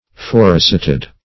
Search Result for " forerecited" : The Collaborative International Dictionary of English v.0.48: Forerecited \Fore`re*cit"ed\, a. Named or recited before.
forerecited.mp3